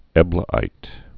(ĕblə-īt)